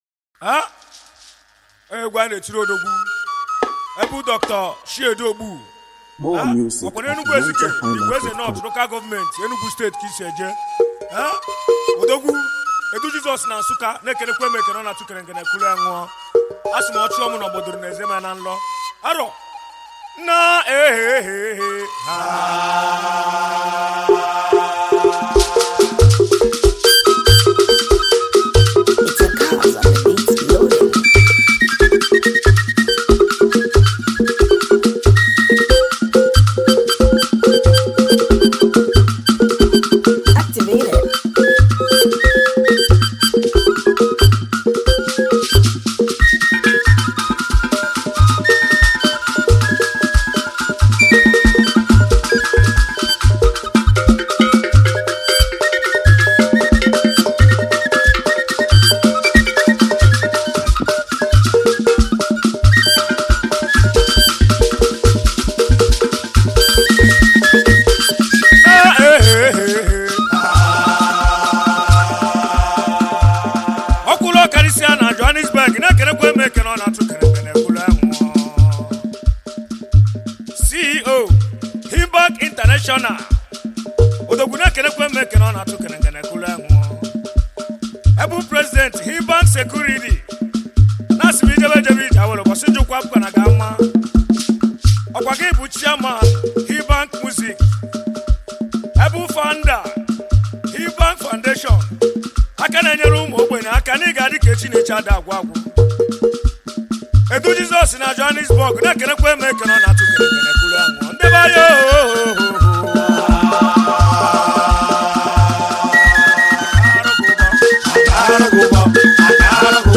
Home » Ogene